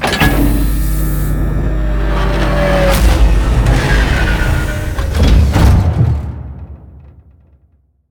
land.ogg